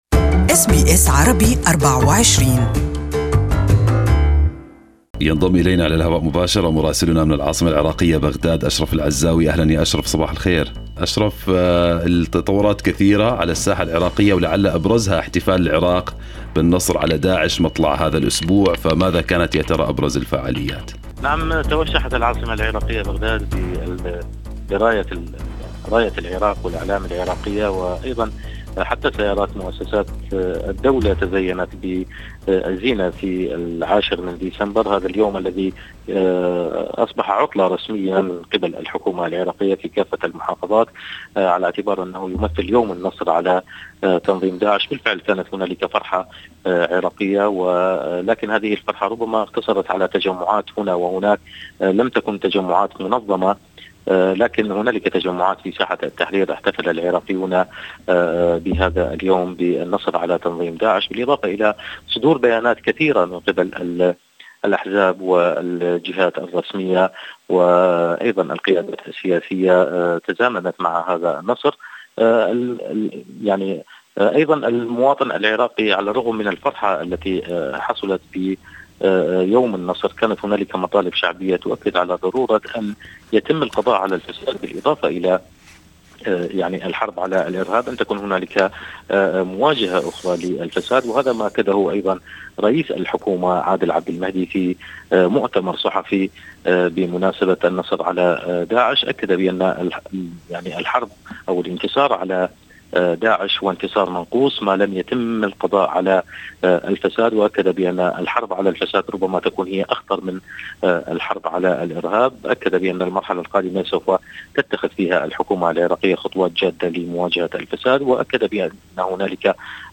Listen to the full report from Baghdad in Arabic above